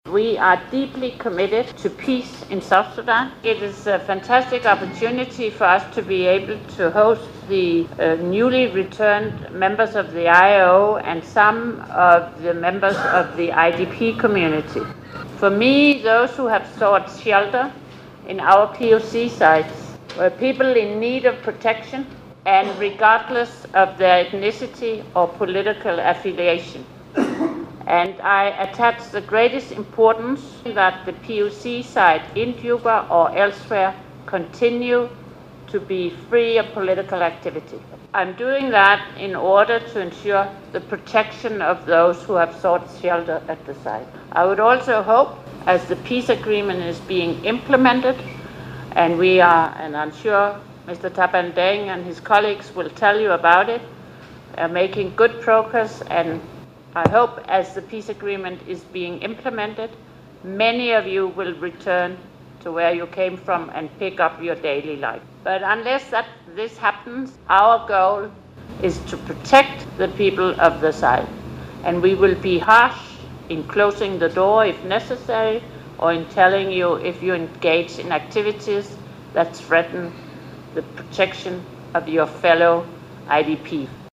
We bring you her statement.